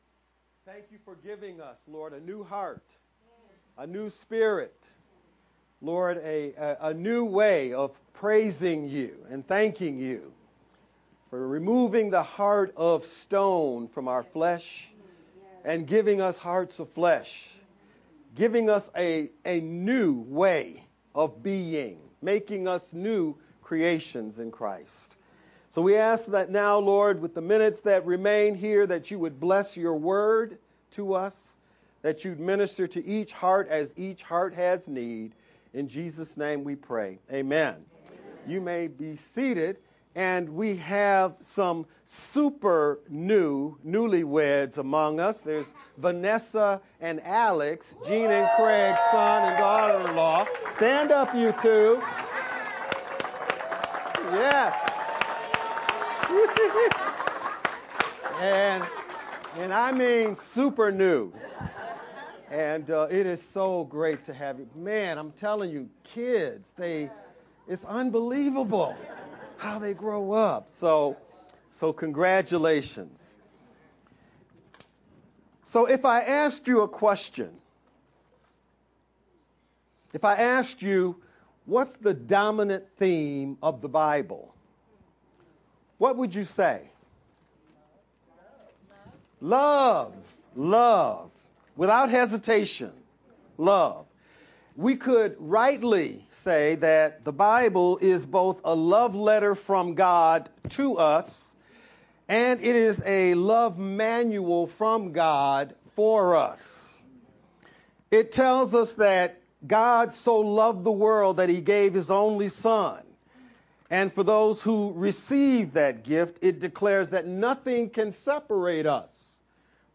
July-27-VBCC-edited-sermon-only_Converted-CD.mp3